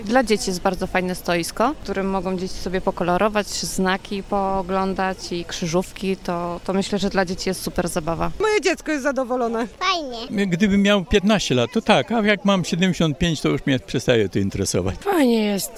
Z tej okazji na rynku staromiejskim odbył się festyn, który przyciągnął wielu mieszkańców.
Uczestnicy festynu podzielili się z nami swoimi wrażeniami.